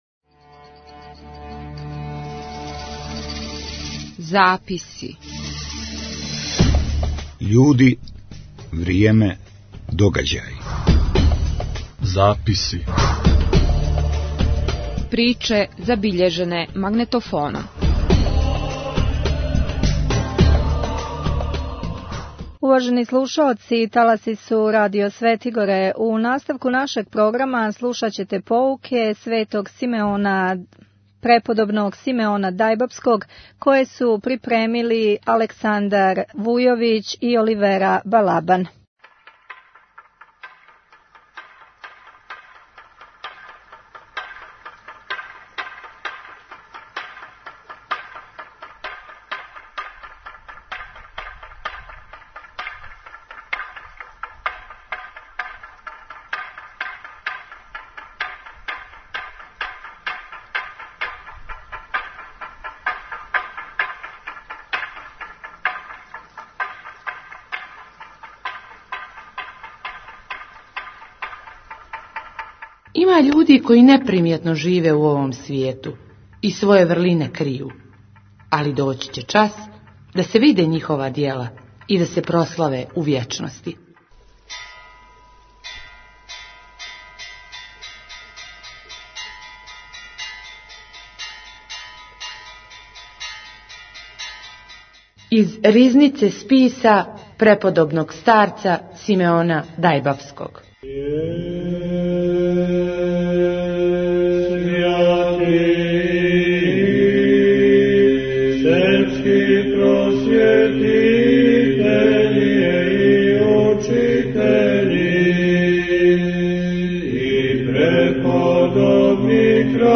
Албум: Zapisi Година: 2010 Величина: 25:36 минута (4.39 МБ) Формат: MP3 Mono 22kHz 24Kbps (CBR) У издању манастира Дајбабе и издавачке куће "Светигора", на Цетињу је 2007. године штампана књига Сабраних списа Преподобног Симеона Дајбабског, аутора јеромонаха Исака Симића.